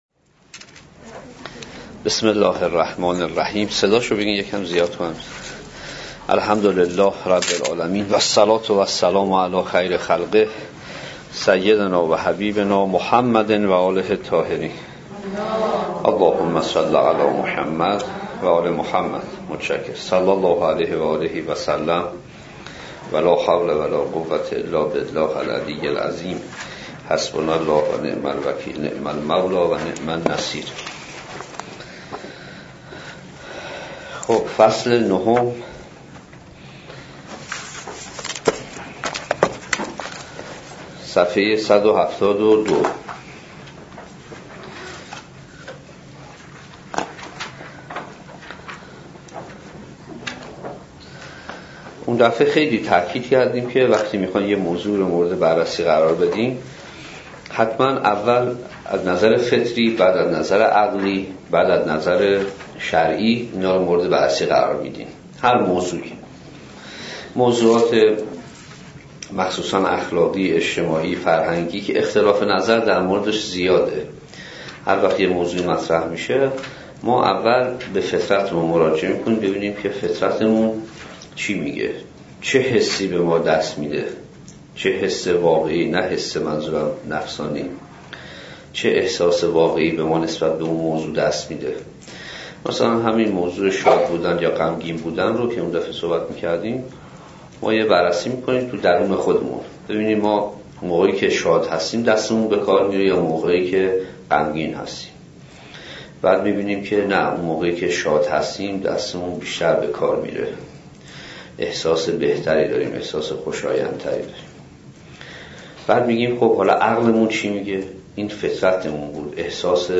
045 - تلاوت قرآن کریم